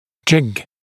[ʤɪg][джиг]небольшая капа-позиционер, изготавливаемая для непрямого приклеивания отдельного брекета